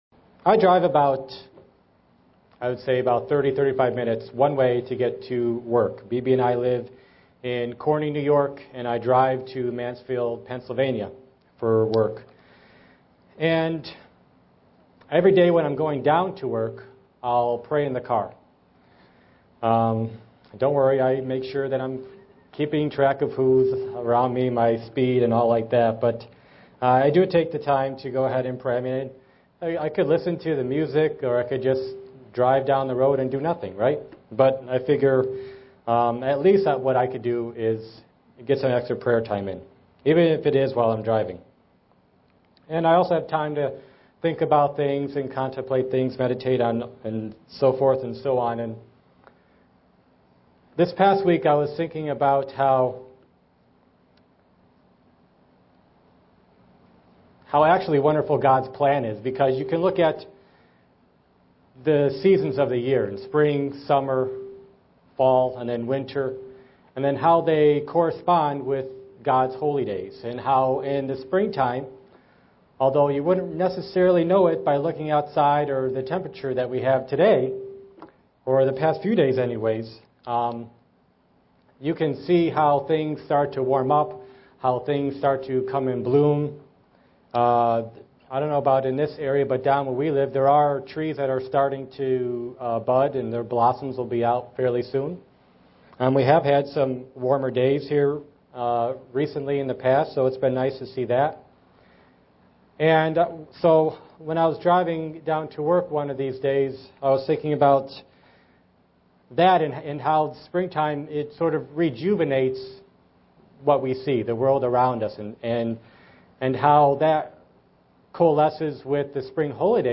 SEE VIDEO BELOW UCG Sermon Studying the bible?